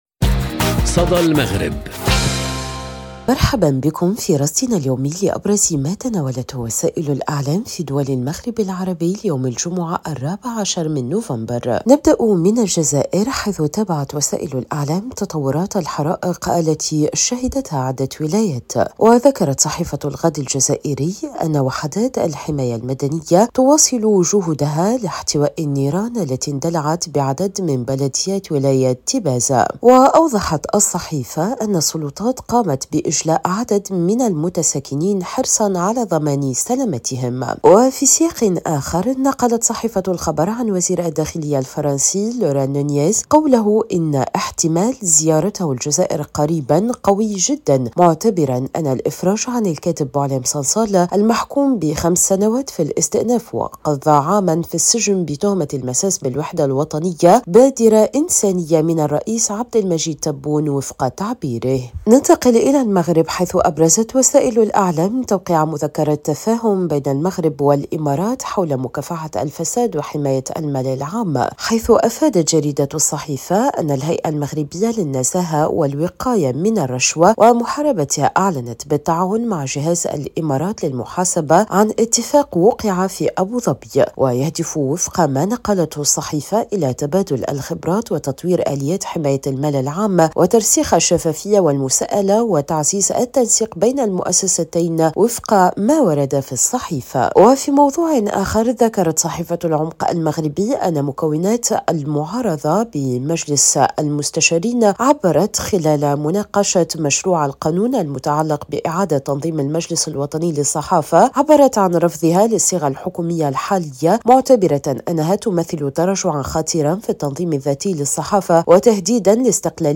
صدى المغرب برنامج إذاعي يومي يُبث عبر راديو أوريان إذاعة الشرق، يسلّط الضوء على أبرز ما تناولته وسائل الإعلام في دول المغرب العربي، بما في ذلك الصحف، القنوات التلفزية، والميديا الرقمية.